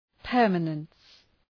Προφορά
{‘pɜ:rmənəns}